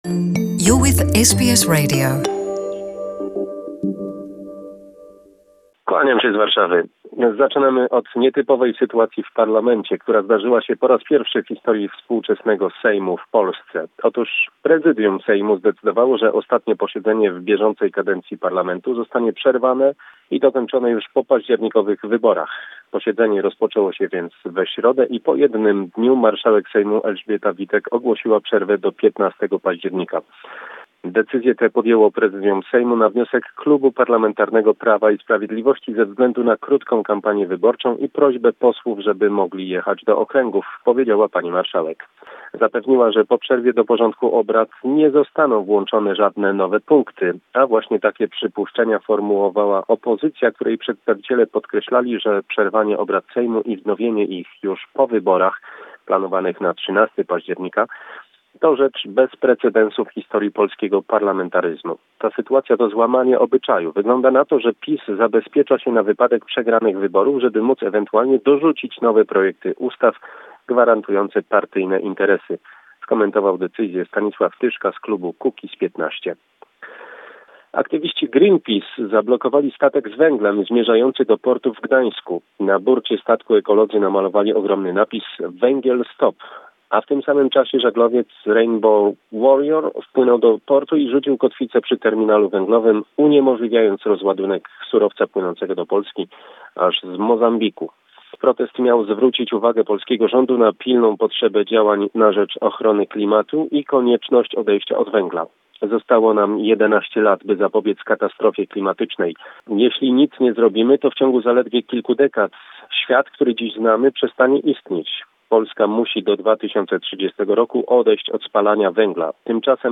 reports from Warsaw... Today about anti-coal campaign of Greenpeace in the port of Gdansk.